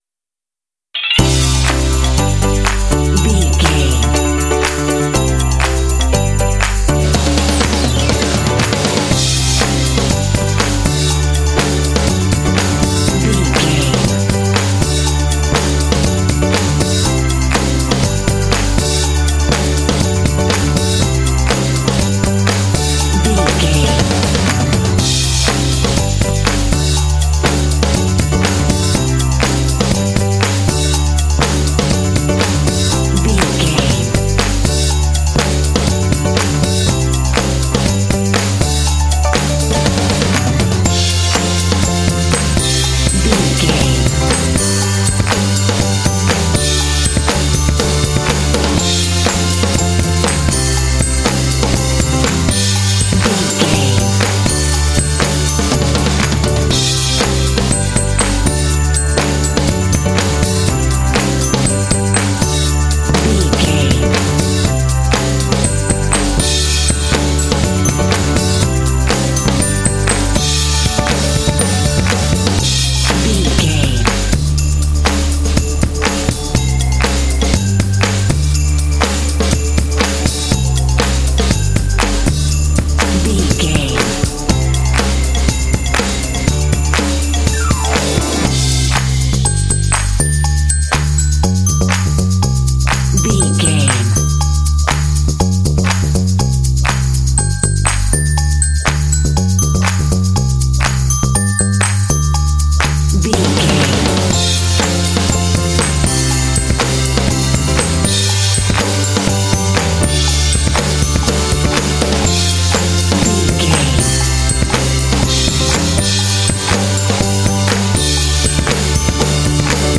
Ionian/Major
D
bouncy
happy
groovy
bright
80s
pop
rock